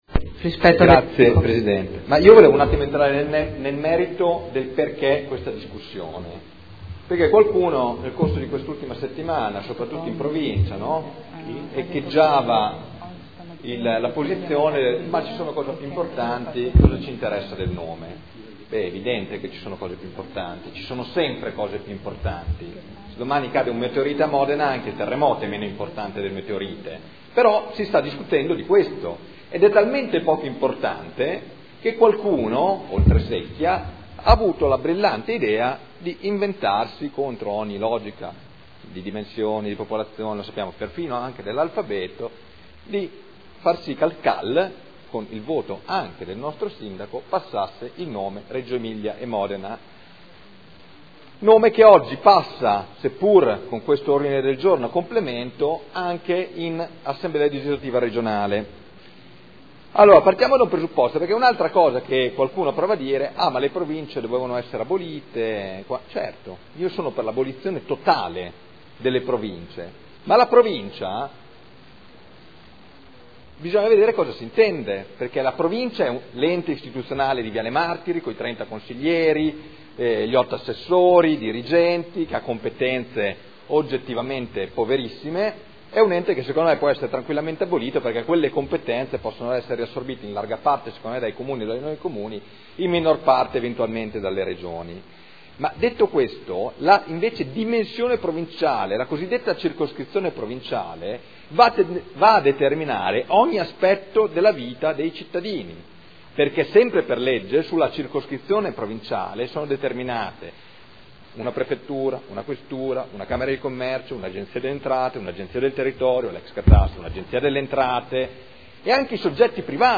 Seduta del 22/10/2012.